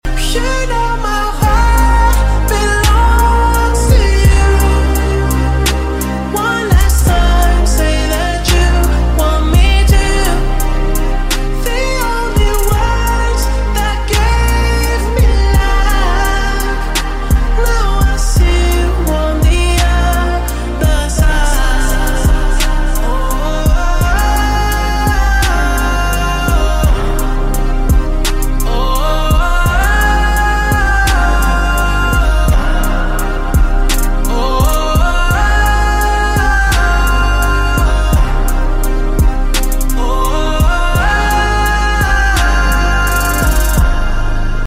final show in his hometown